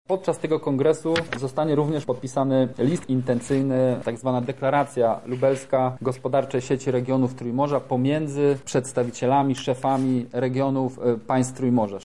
O tym co wydarzy się podczas kongresu opowiedział wicemarszałek województwa lubelskiego Michał Mulawa: